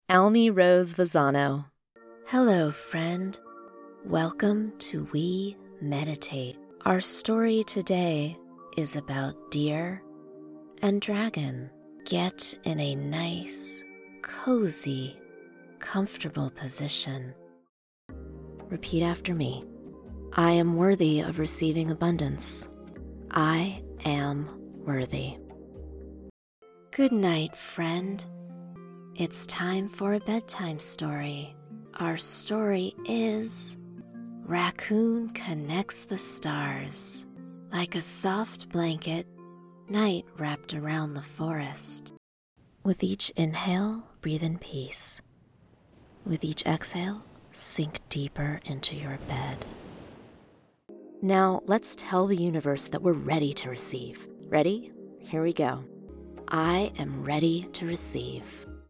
Meditation VO Reel